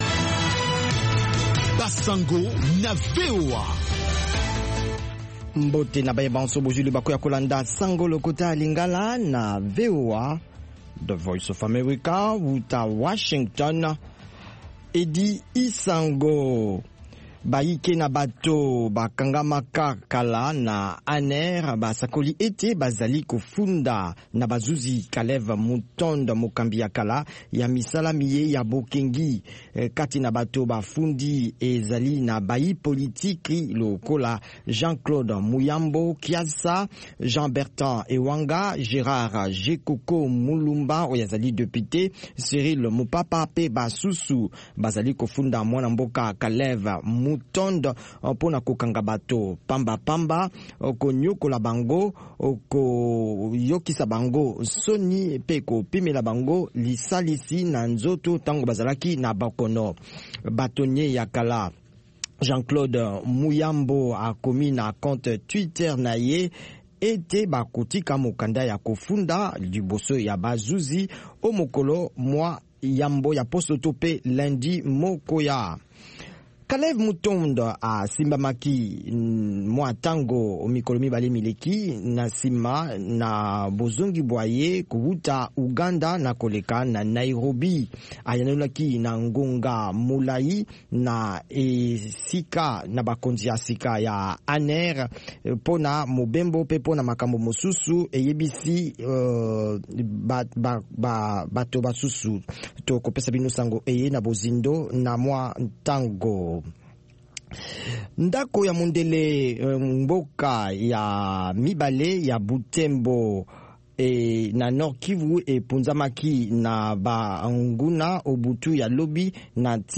Radio
Basango na VOA Lingala